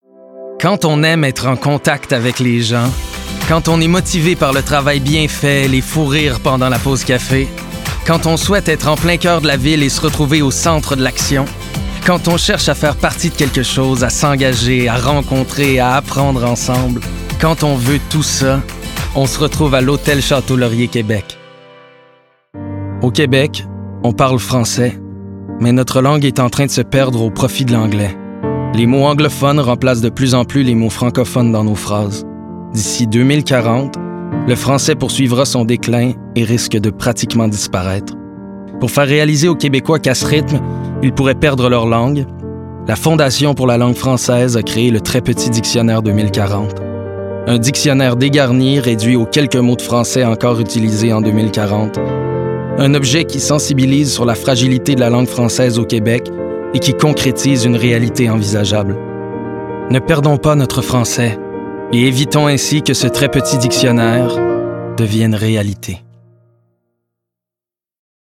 | Comédien
Démo_voix_2024.mp3